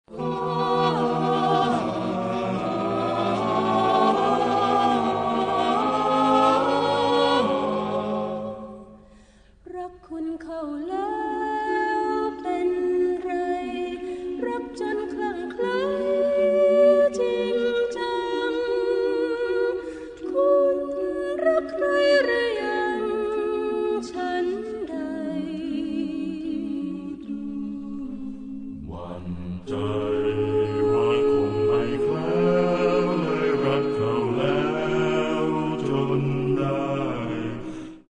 โซปราโน
อัลโต
เทเนอร์
เบส